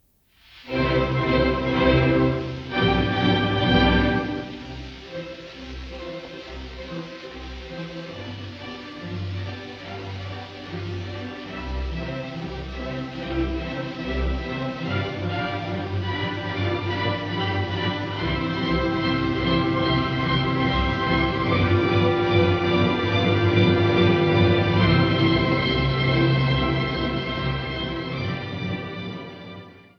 Opernszenen
Gewandhausorchester Leipzig, Gustav Brecher, Paul Schmitz
Der zweite Teil der CD-Serie enthält auf zwei CDs insgesamt 15 Ausschnitte von acht Opern in Aufnahmen von 1929 bis 1945.